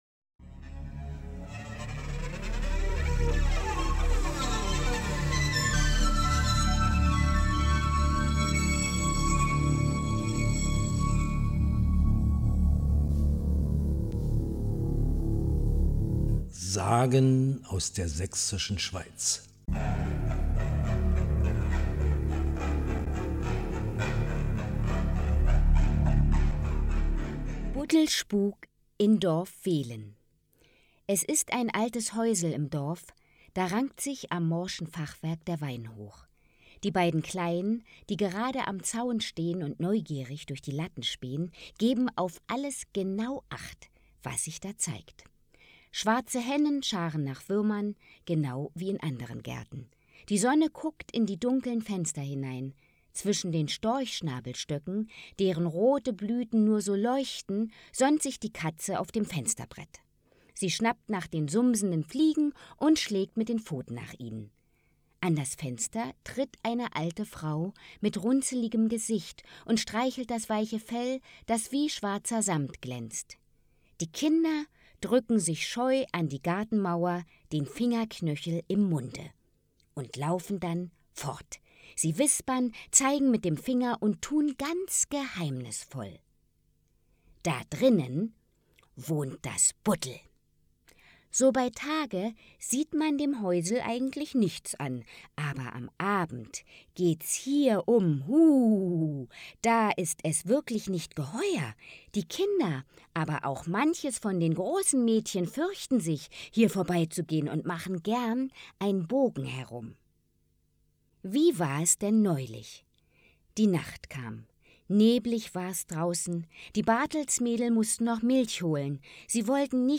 Literatur Märchen / Sagen